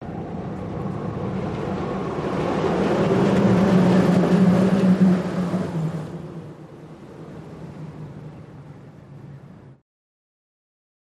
Monorail, Disneyland, Smooth By